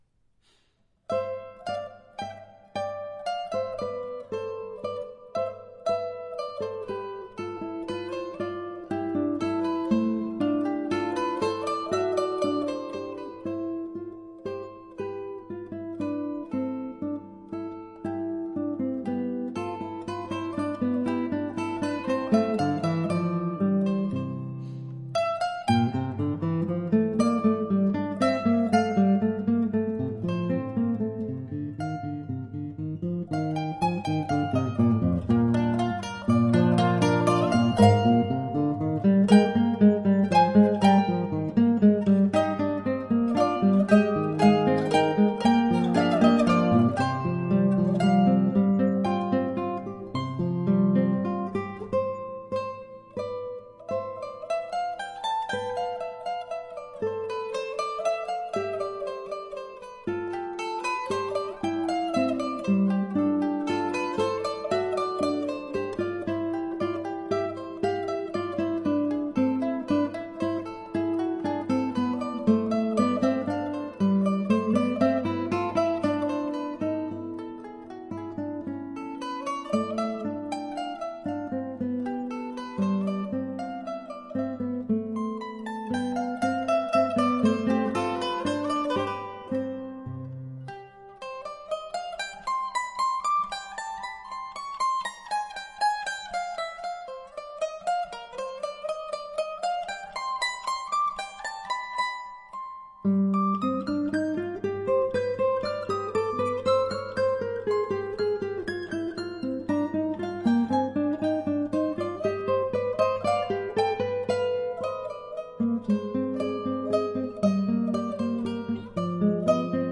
Mandola
Mandoloncello
Mandolin
Guitar